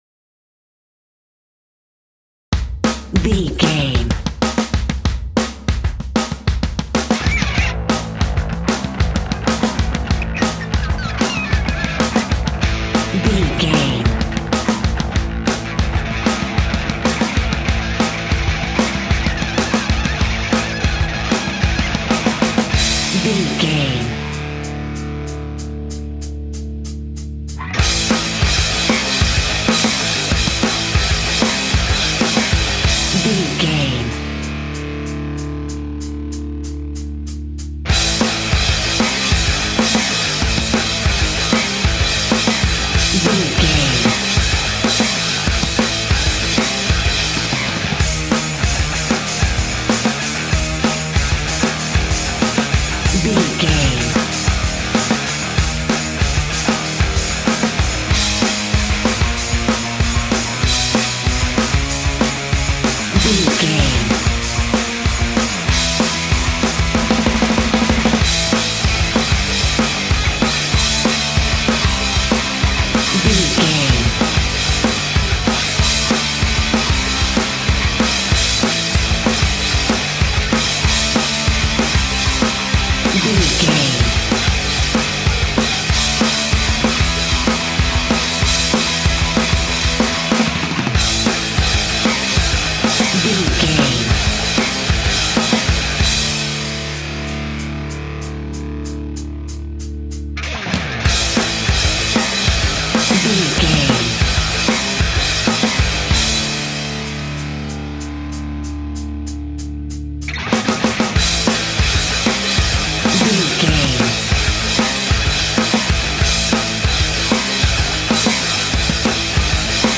Epic / Action
Fast paced
Aeolian/Minor
Fast
hard rock
distortion
punk metal
instrumentals
Rock Bass
heavy drums
distorted guitars
hammond organ